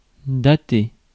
Ääntäminen
IPA : /deɪt/